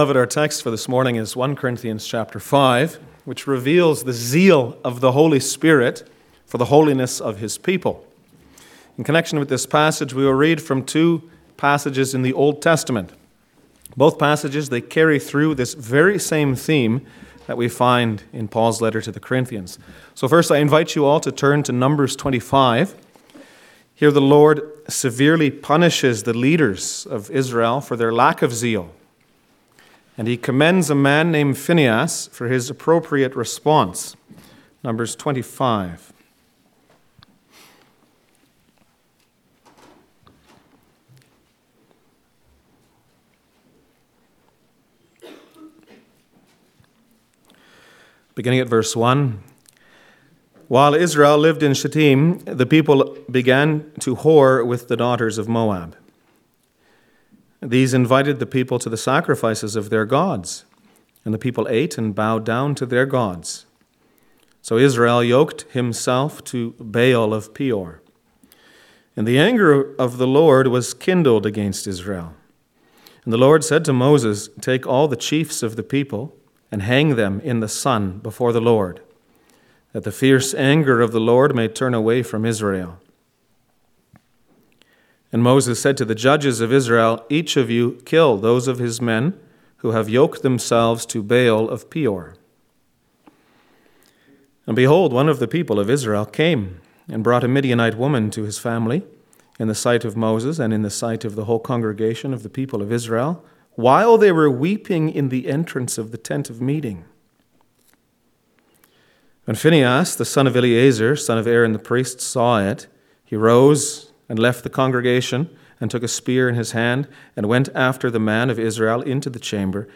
Passage: 1 Corinthians 5 Service Type: Sunday Morning